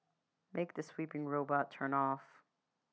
Canadian_Speaking_English_Speech_Data_by_Mobile_Phone